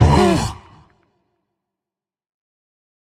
sounds / mob / warden / hurt_1.ogg
hurt_1.ogg